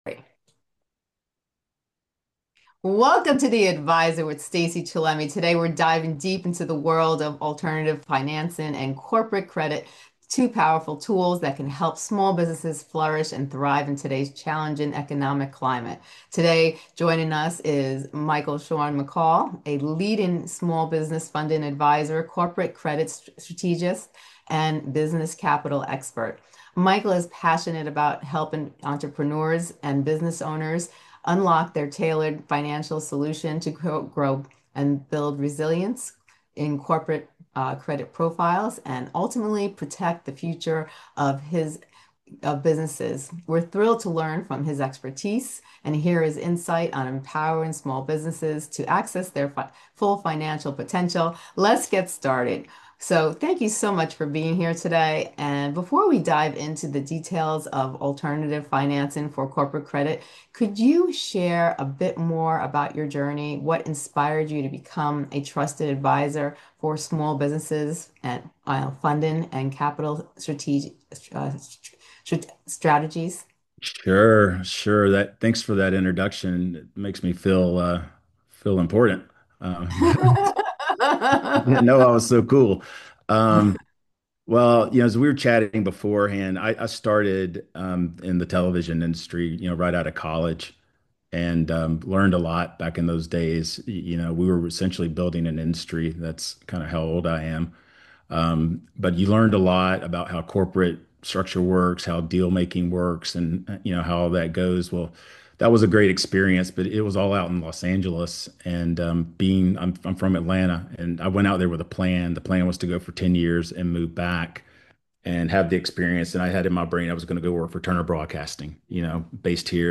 entrepreneur